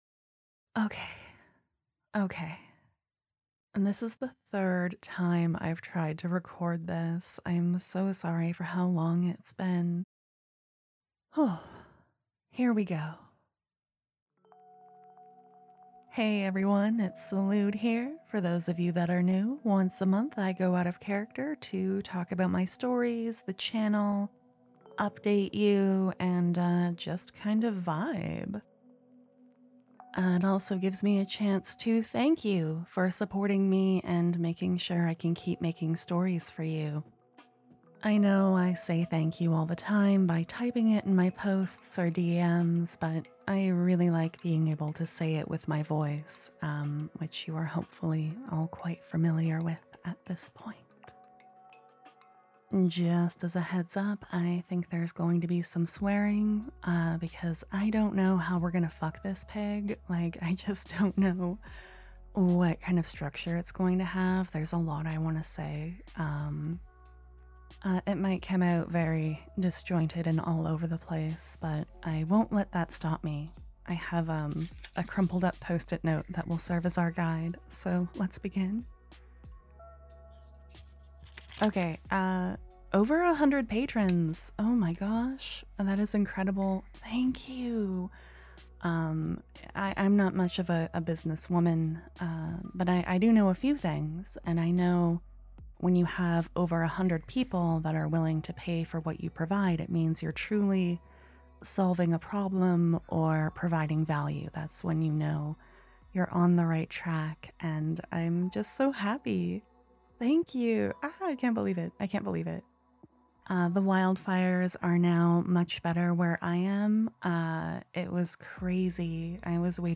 Please be aware some parts of this audio aren't super-sleepy or anything, and the recording is lower quality / less edited than my stories are~